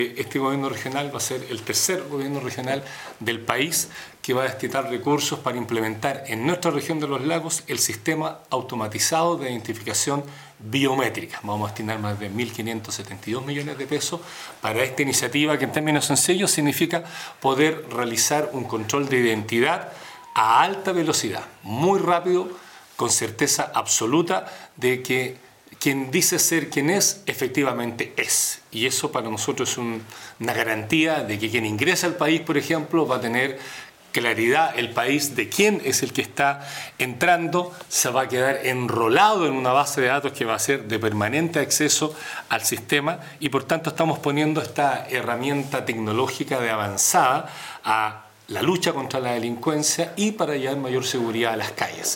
Este sistema ejecutado por la Policía de Investigaciones contaría con una inversión de 1.572 millones de pesos por parte del Gobierno Regional, que permitirá identificar en tiempo real a las personas que ingresan por los pasos fronterizos de la región, como lo explicó el Gobernador Regional Patricio Vallespin.